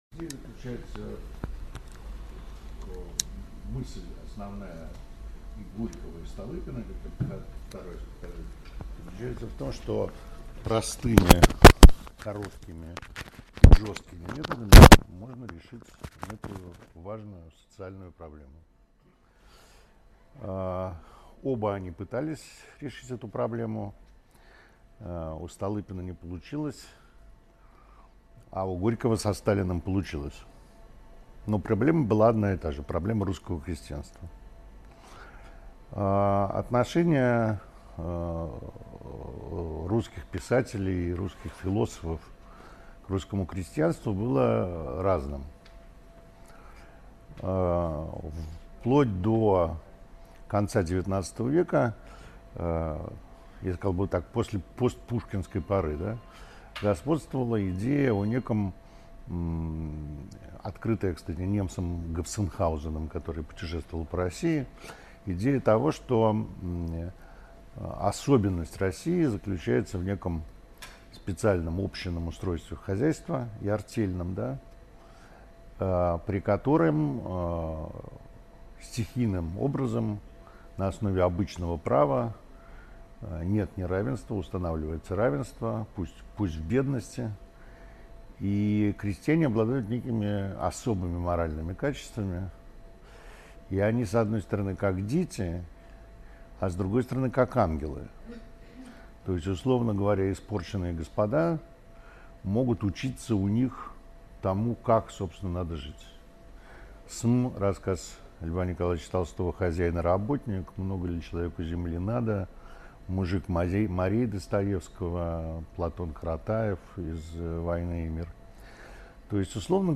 Аудиокнига Горький – Столыпин. Россия. XX век в параллельных жизнеописаниях важнейших деятелей | Библиотека аудиокниг